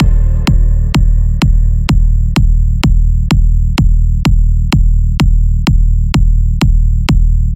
以下のサウンドはサンプルパックだけを使用したものです。
サンプルパックに入っているEDMらしさがすごいキックたち↓
どのデモもサンプルパックのものを貼り付けて鳴らしているだけですがもはやこのままEDMに使えそうです。